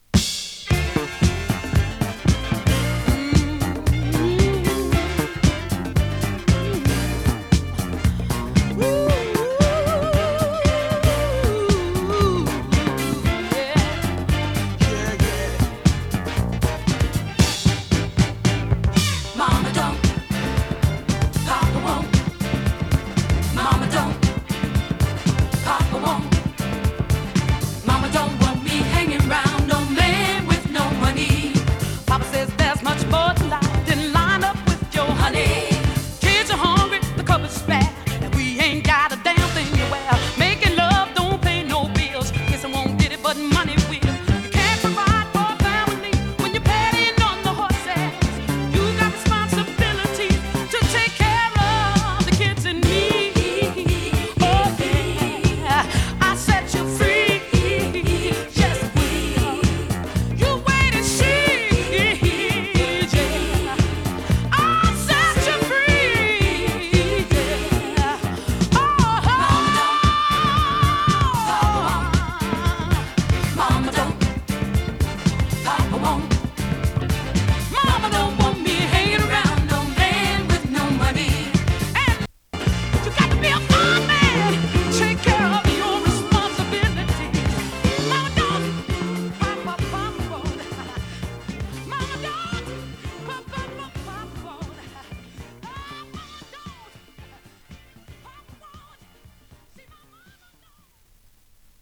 キャッチーなサビのダンクラ